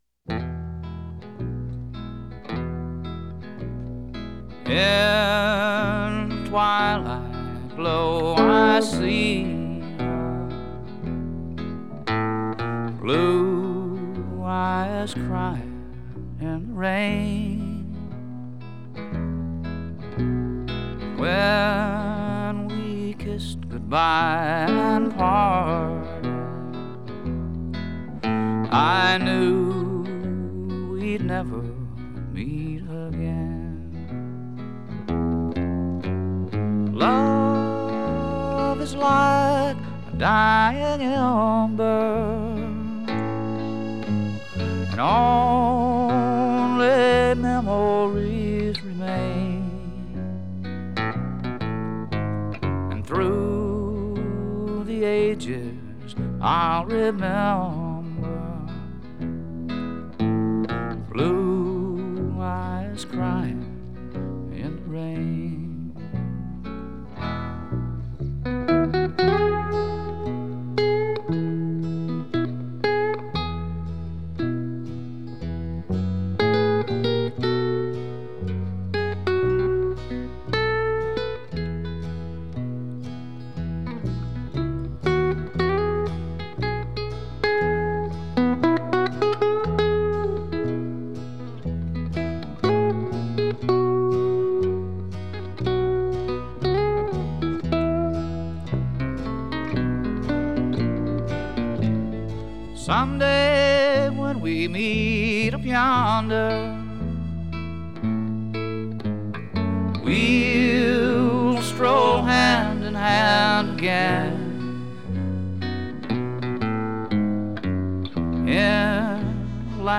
hauntingly beautiful
Simply beautiful, honest, and bare.